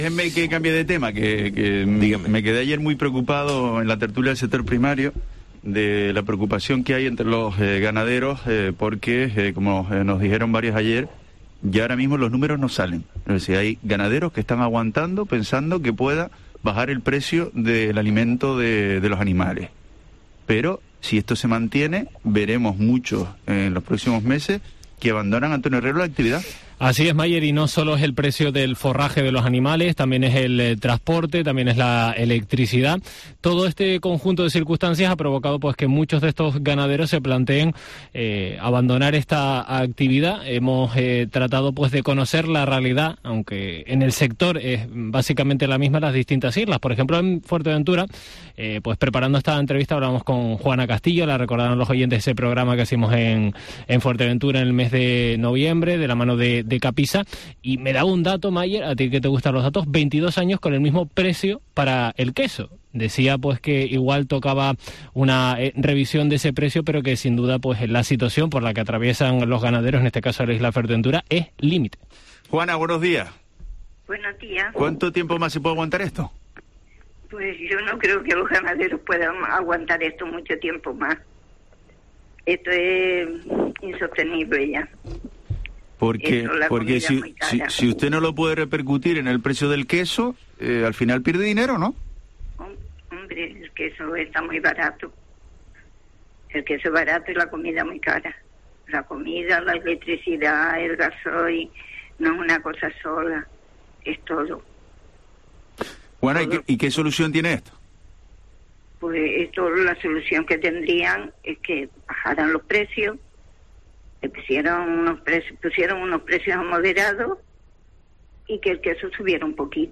En los micrófonos de COPE Canarias